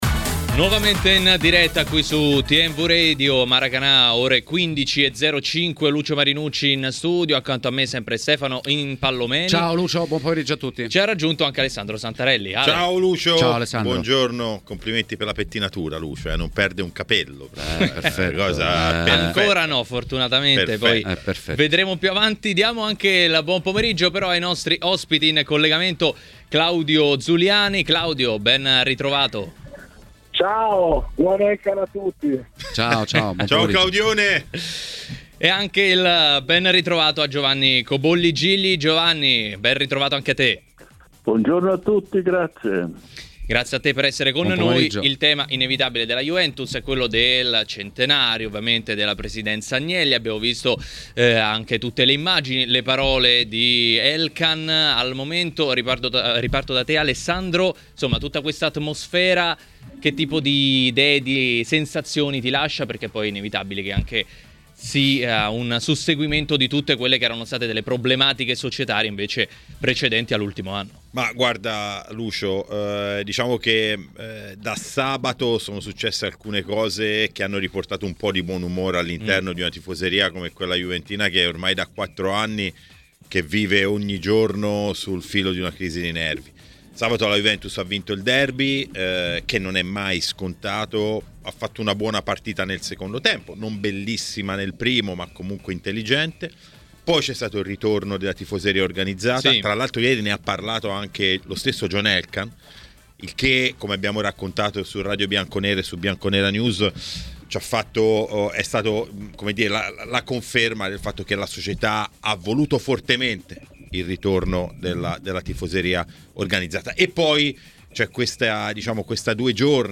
Giovanni Cobolli Gigli, ex presidente della Juventus, ha parlato a TMW Radio, durante Maracanà, del momento del club bianconero e non solo.